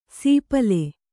♪ sīpale